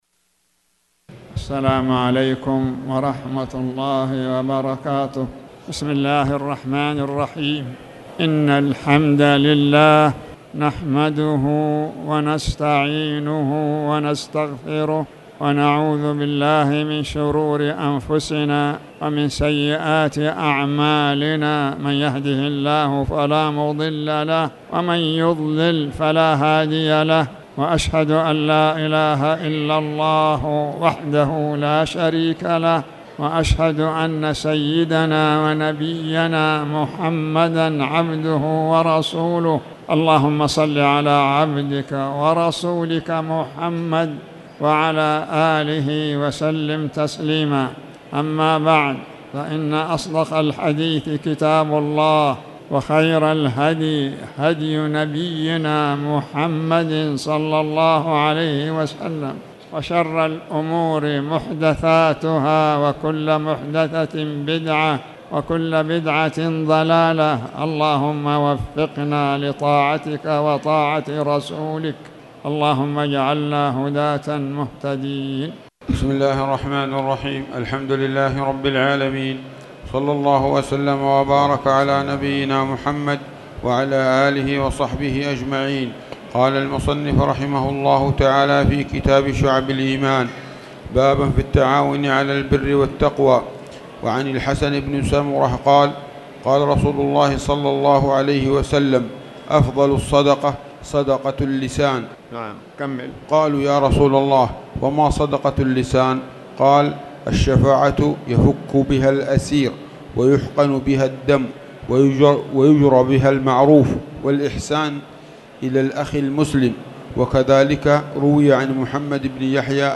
تاريخ النشر ٩ صفر ١٤٣٩ هـ المكان: المسجد الحرام الشيخ